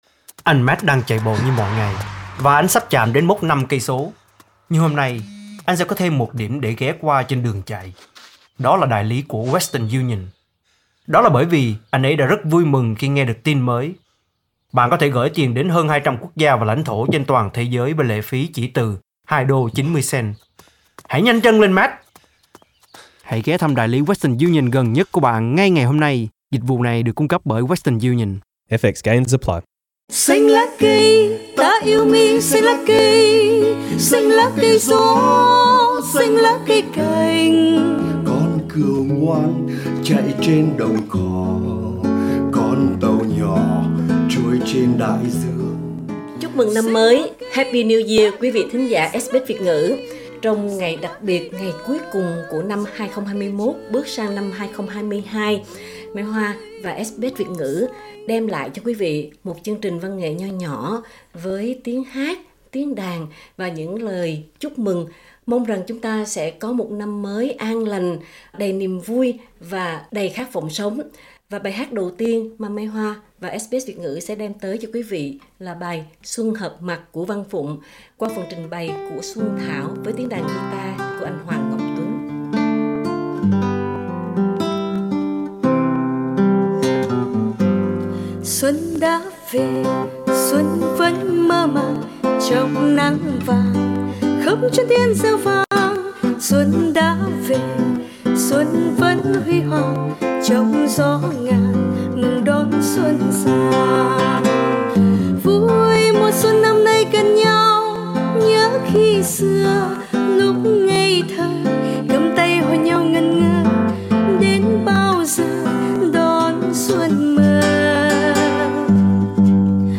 Khép lại một năm cũ đầy lo âu, cùng bước qua năm mới và cùng chúc nhau những tin yêu, những họp mặt hẹn hò và tay lại cầm tay. Chương trình văn nghệ nhỏ được thực hiện đặc biệt dành cho quý khán thính giả SBS Việt ngữ với lời chúc màu xanh lá cây, màu của sự sống, của reo vui và an hoà.